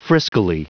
Prononciation du mot friskily en anglais (fichier audio)
Prononciation du mot : friskily